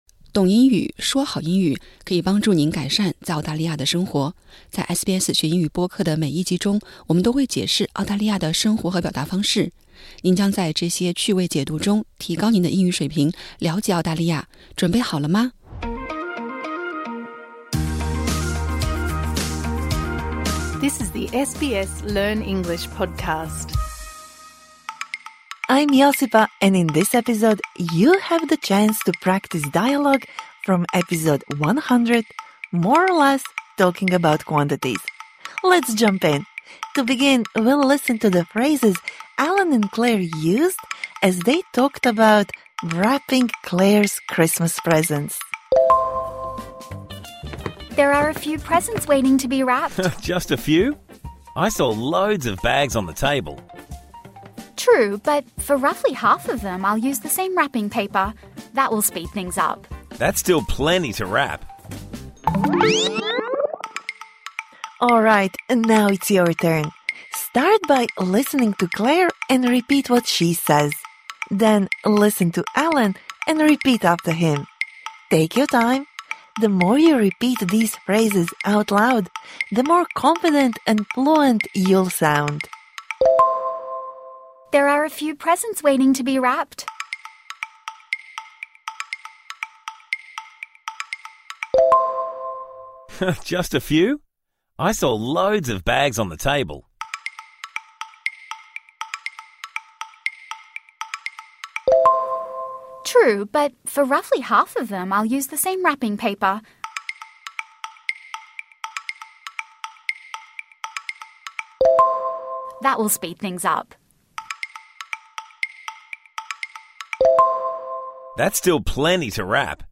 本期为《学英语》第100集的附加集，对这一集中学到的单词和短语进行互动口语练习。